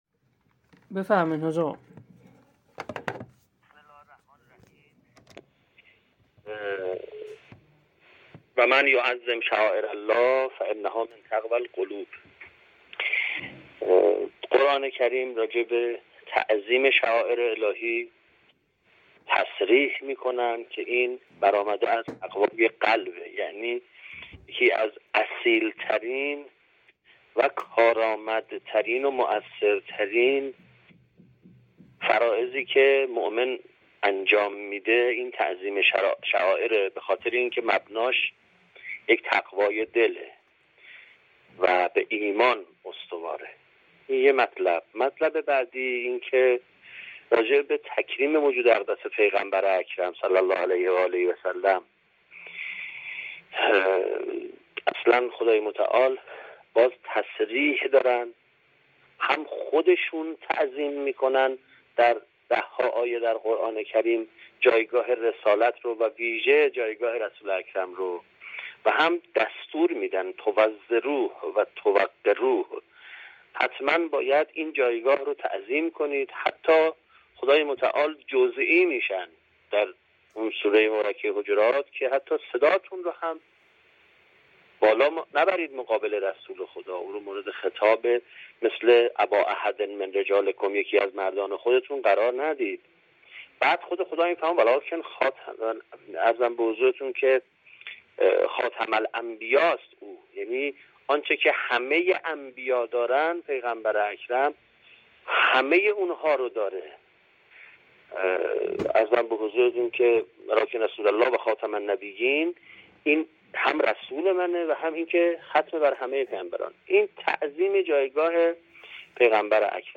در گفت‌وگو با خبرنگار فرهنگی تسنیم